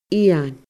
eun /ian/